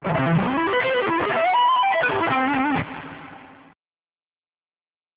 Advanced Sweep Picking
Another possiblity, sweeping 7th's with slides and in octaves pairing.